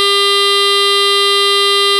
clarinet:
sound-clarinet.wav